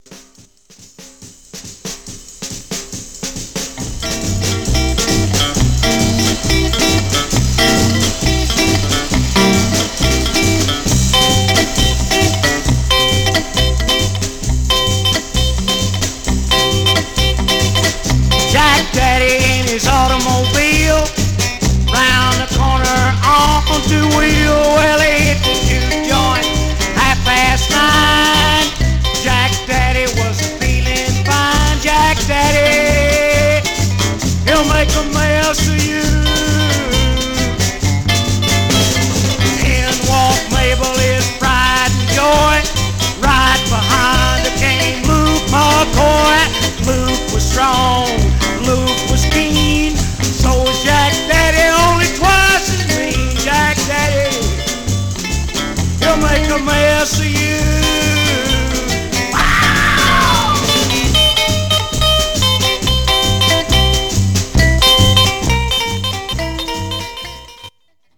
Stereo/mono Mono
Rockabilly